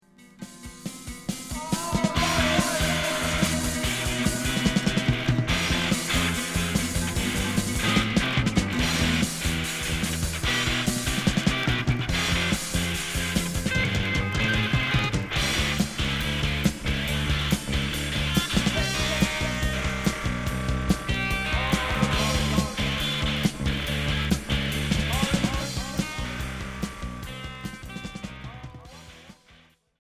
Street punk expérimental Unique 45t